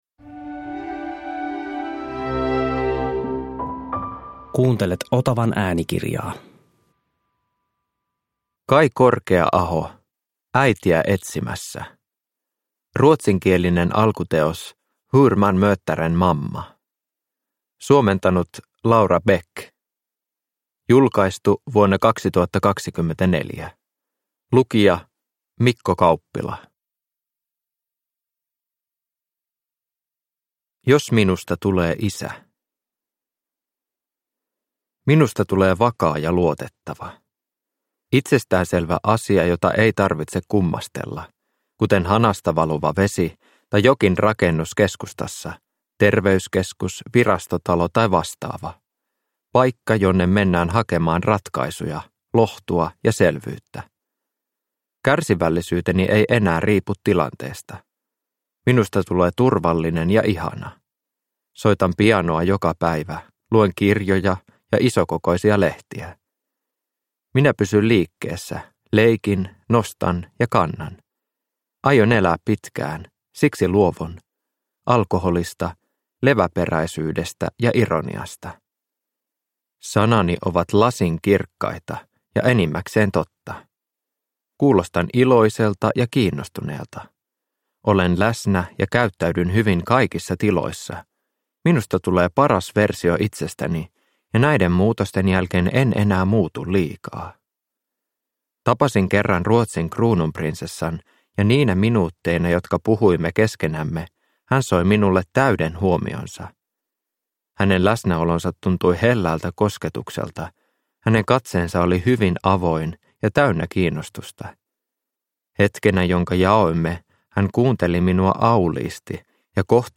Äitiä etsimässä – Ljudbok